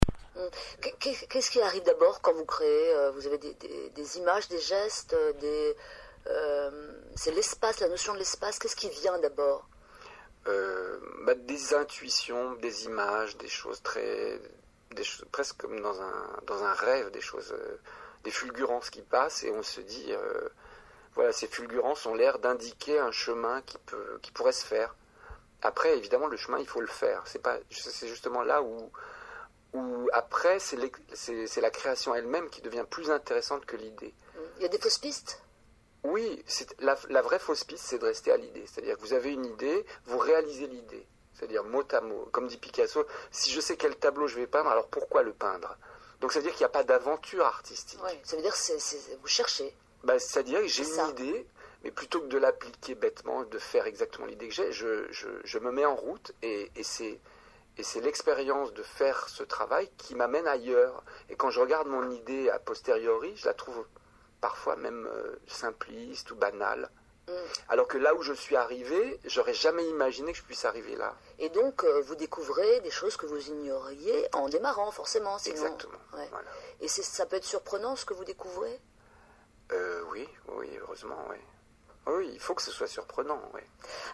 C’est ce qu’ Angelin Preljocaj faisait hier à la radio.